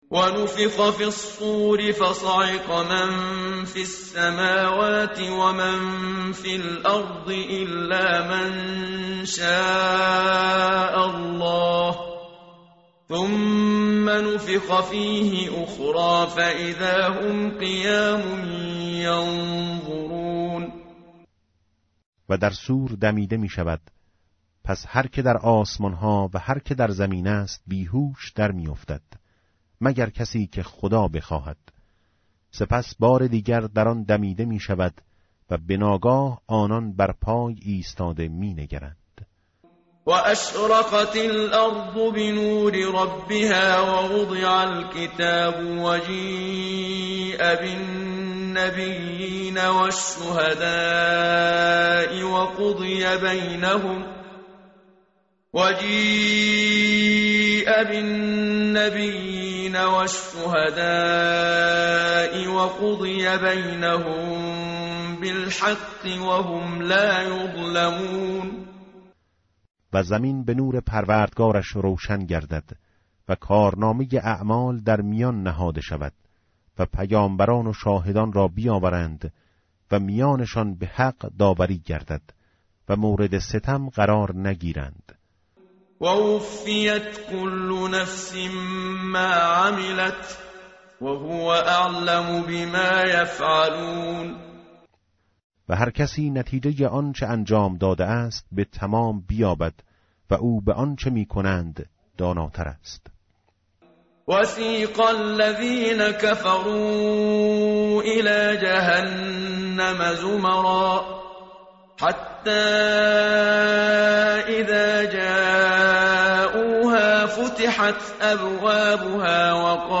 متن قرآن همراه باتلاوت قرآن و ترجمه
tartil_menshavi va tarjome_Page_466.mp3